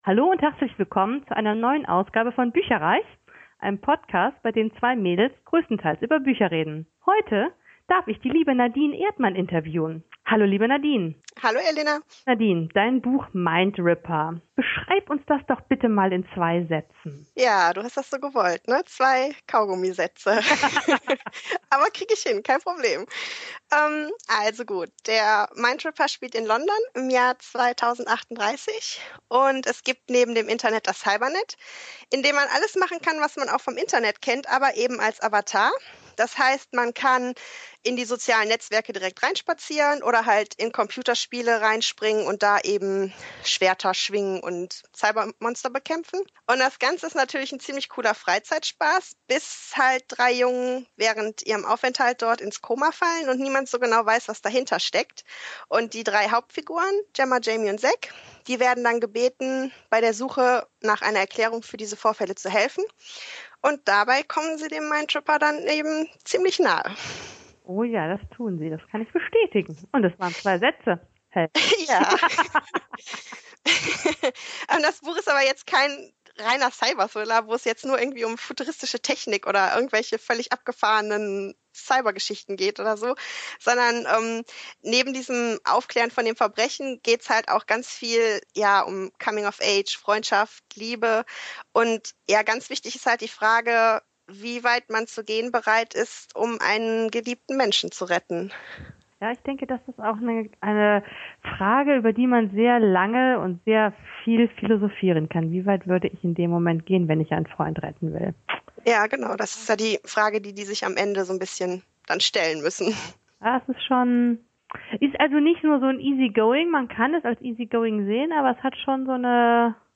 Entschuldigt bitte, dass die Tonqualität leider nicht auf unserem üblichen Niveau ist, da das Interview via Skype geführt und aufgenommen wurde.